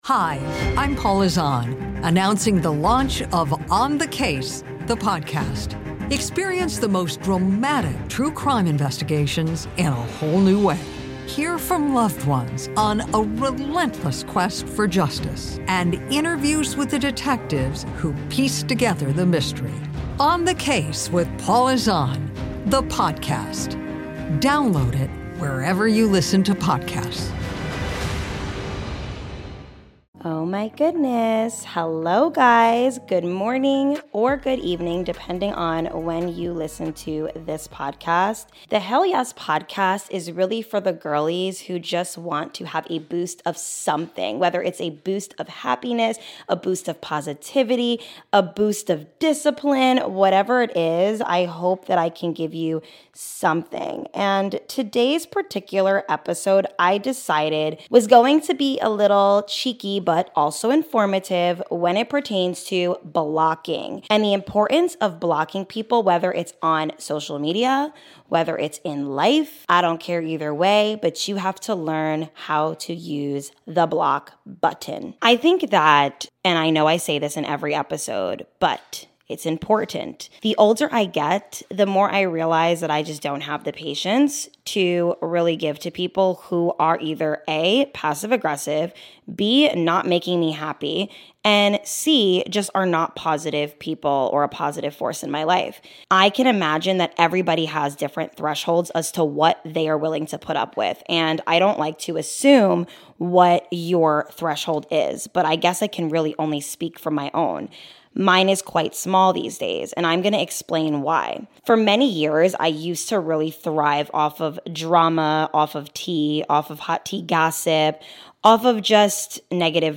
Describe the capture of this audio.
I discuss why this is crucial for mastering peace and ascension into the best version of you!! also side bar if you hear my dryer in the background I'm sorry lol!